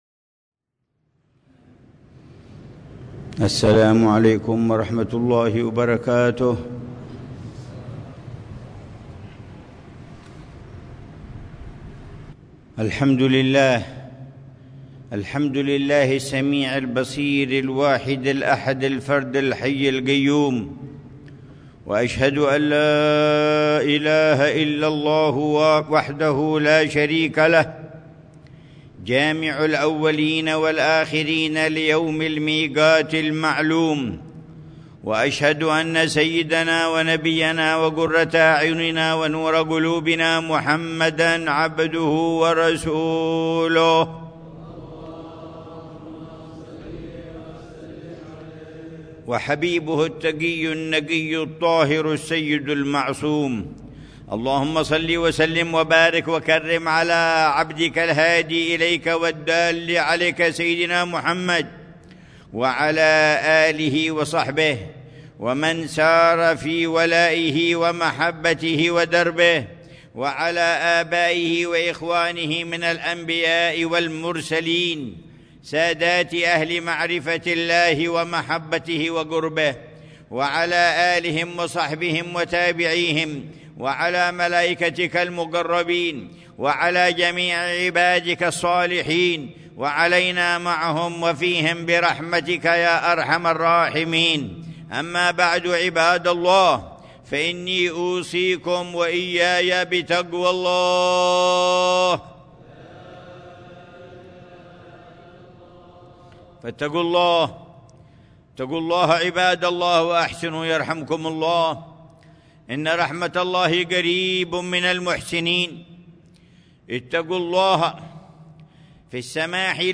خطبة الجمعة للعلامة الحبيب عمر بن محمد بن حفيظ في جامع الإيمان، بحارة الإيمان، عيديد، مدينة تريم، 11 ربيع الثاني 1447هـ بعنوان: